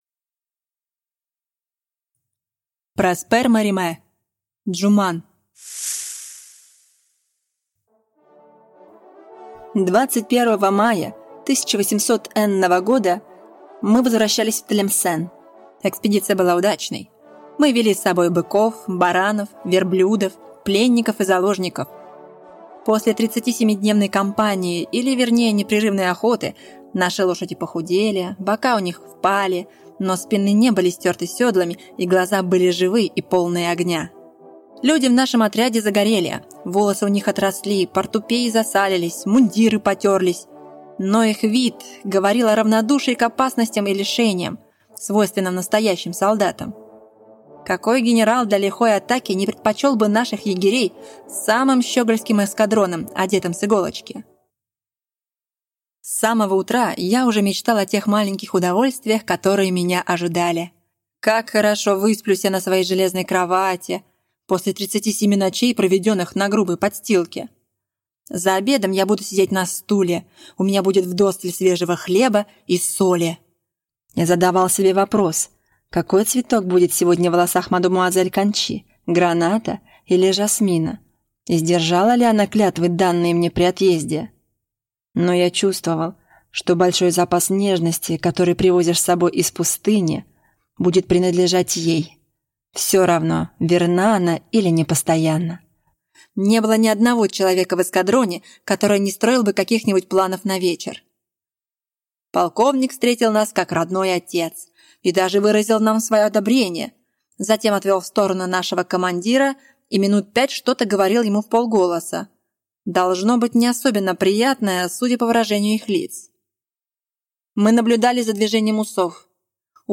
Аудиокнига Джуман | Библиотека аудиокниг